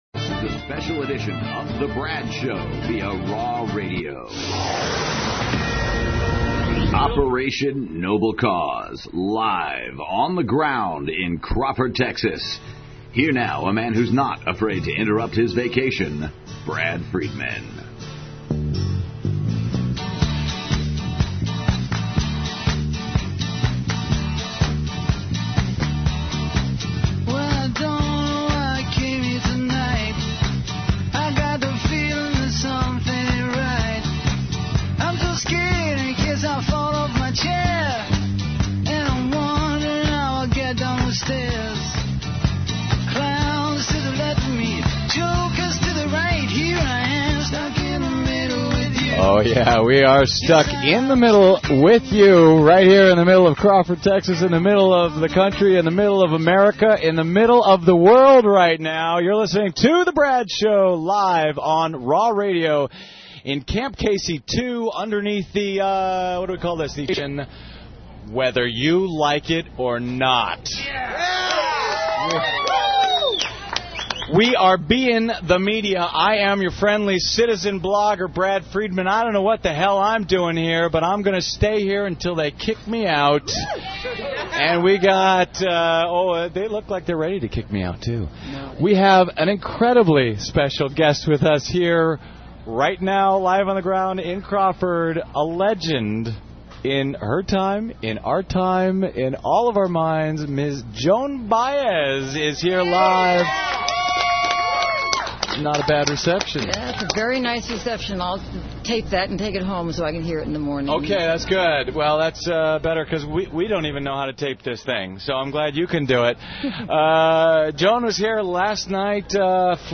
Live and Deep in the Heart of Texas!